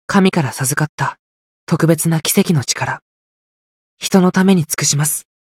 觉醒语音 神から授かった特別な奇跡の力、人のために尽くします 媒体文件:missionchara_voice_36.mp3